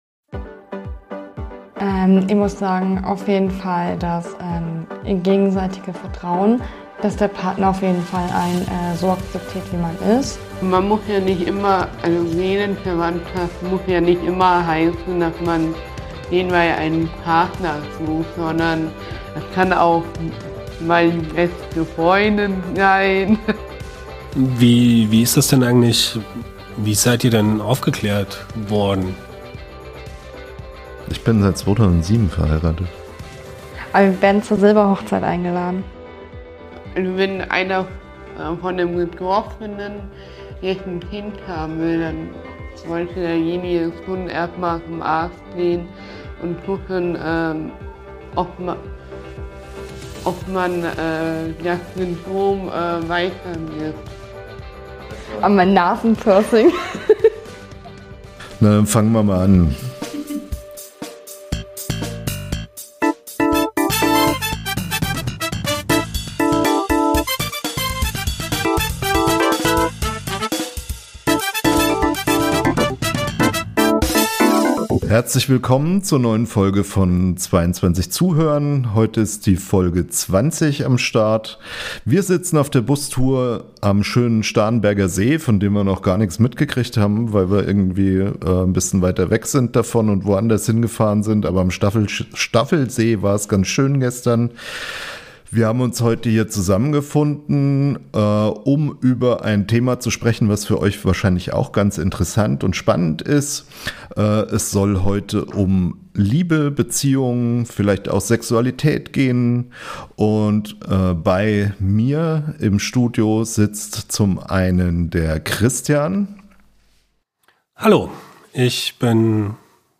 Wir sprechen zu fünft über Beziehungen, Liebe und Partnerschaft.
Ein Gespräch über Liebe, Beziehungen und Partnerschaft